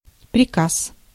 Ääntäminen
IPA: /prʲɪˈkas/